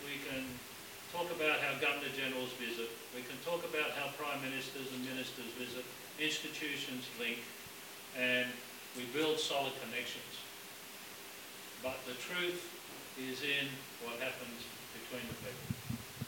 This is the sentiment of the Governor-General of the Commonwealth of Australia, David Hurley while speaking at a reception at the Australian High Commission last night.